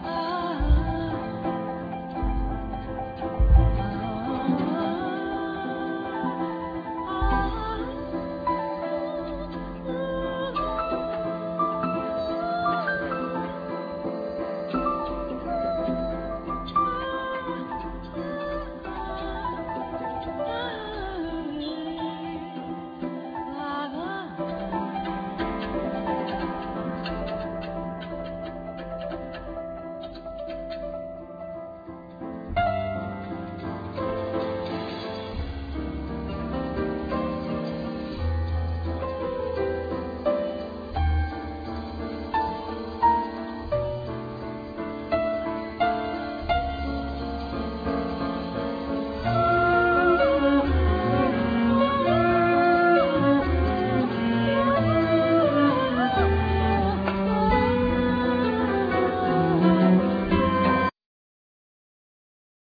Drums, Percussions
Voice
Piano
Cello